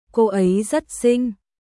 コー エイ ザット シン🔊